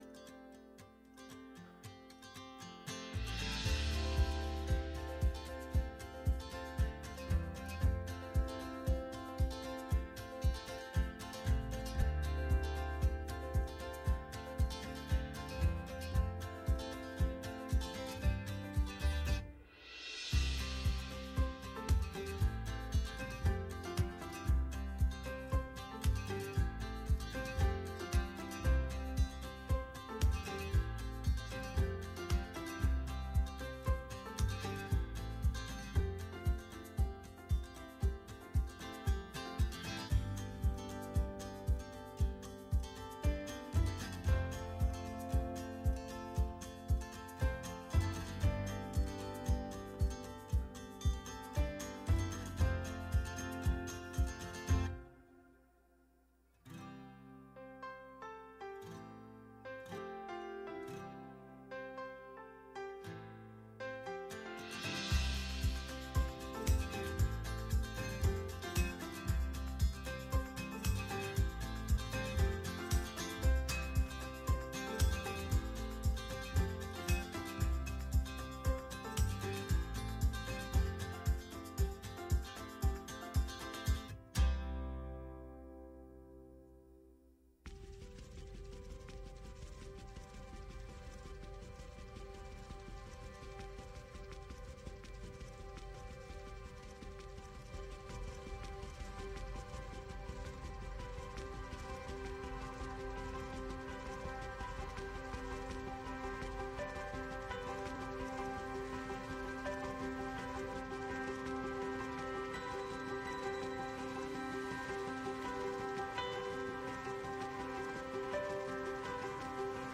Sermons | Saanich Baptist Church